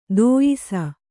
♪ dōyisa